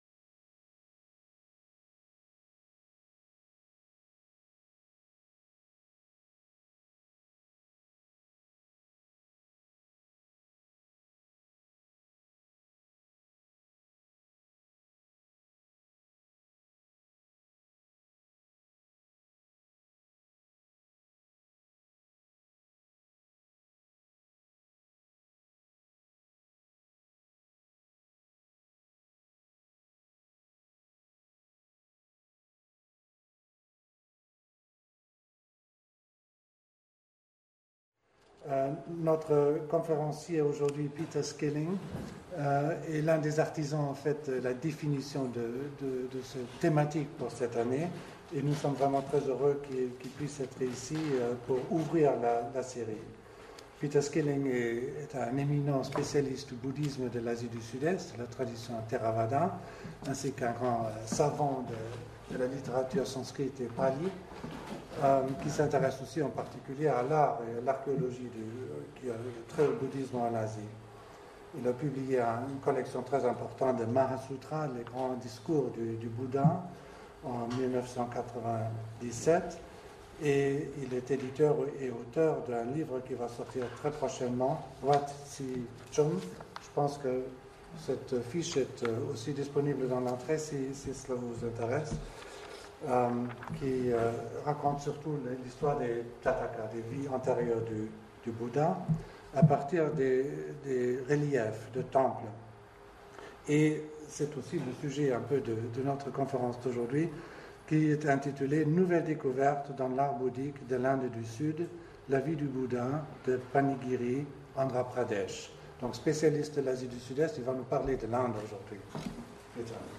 The phase presented in this lecture dates from the first to the third centuries CE. Two architraves from a gateway of the stupa were unearthed in 2005. One is carved with scenes from the life of the Buddha.